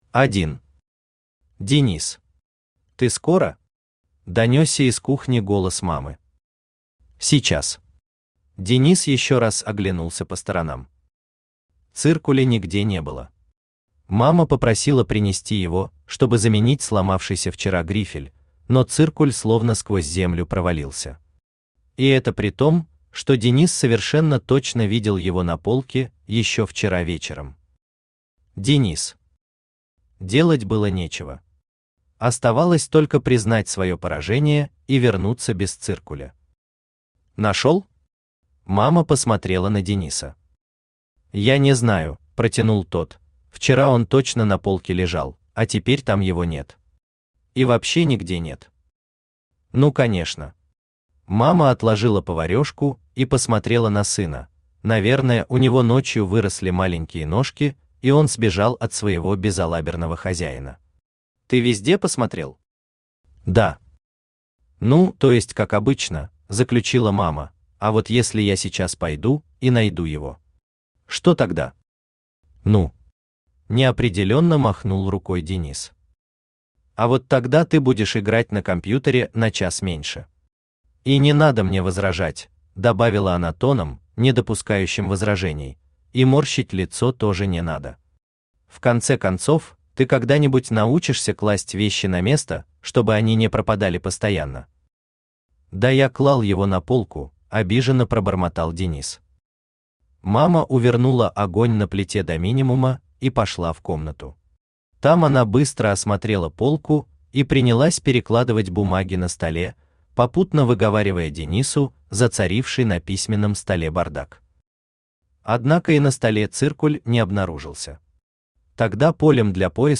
Аудиокнига Портал | Библиотека аудиокниг
Aудиокнига Портал Автор Сергей Юрьевич Прокудин Читает аудиокнигу Авточтец ЛитРес.